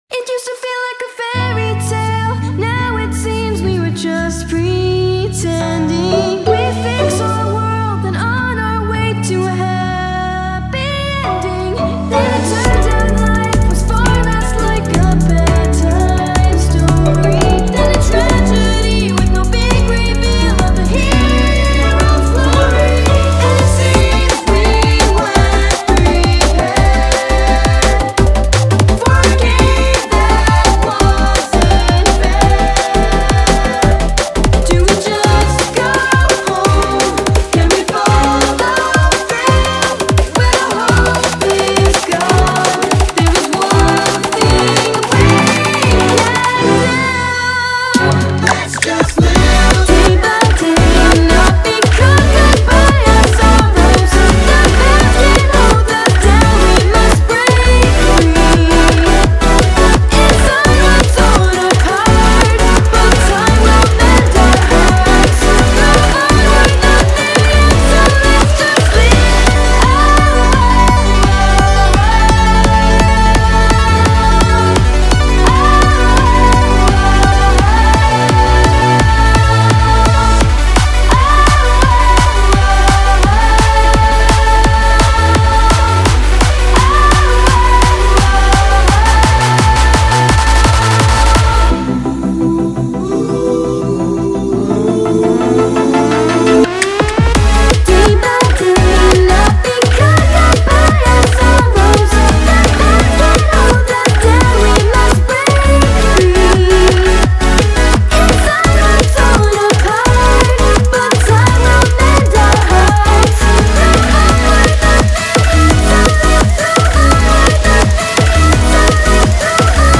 BPM170
Audio QualityPerfect (High Quality)
A remix of Opening theme of Volume 4.